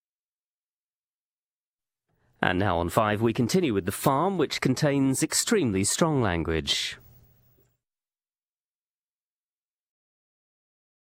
IDENT | Plain Animation | A plain, musicless ident. 267kb 11.1sec